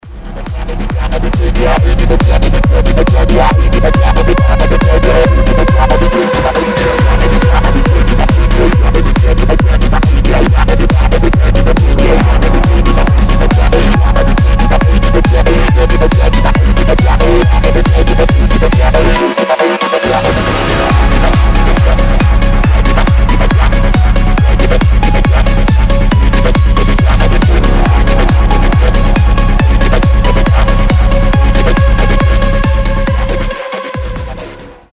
• Качество: 320, Stereo
быстрые
psy-trance
этнические
Техно